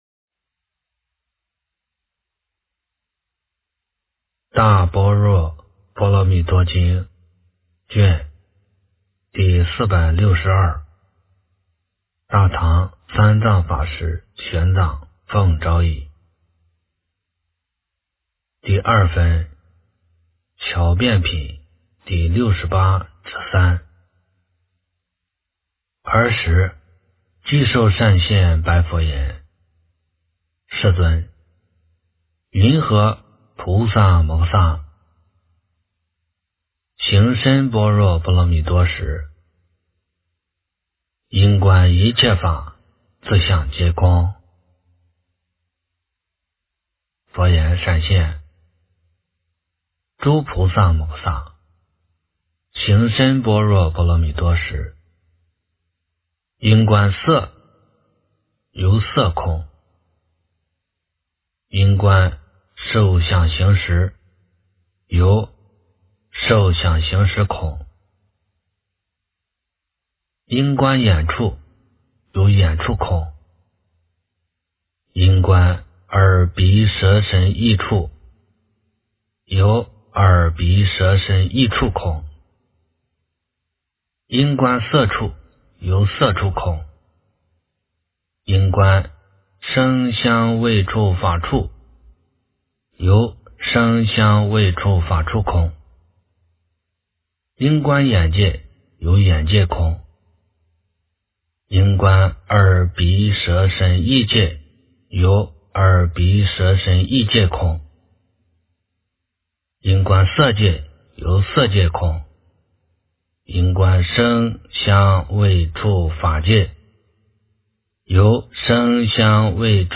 大般若波罗蜜多经第462卷 - 诵经 - 云佛论坛